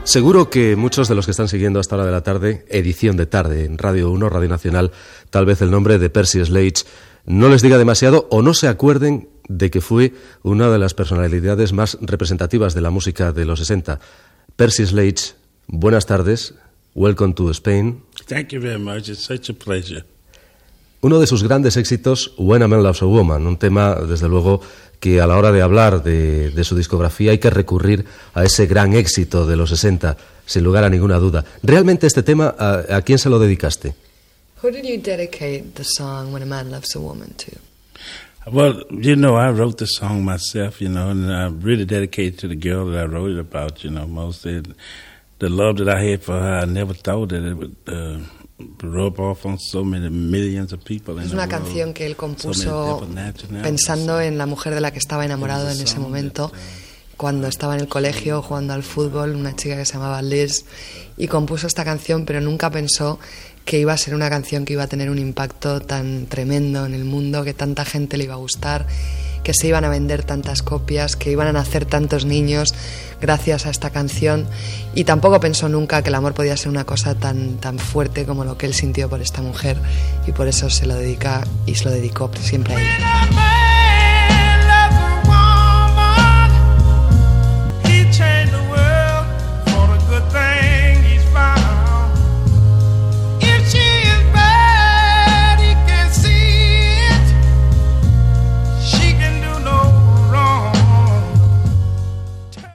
Identificació del programa i entrevista al cantant Percy Sledge